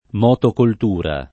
motocoltura [ m q tokolt 2 ra ] s. f. (agr.)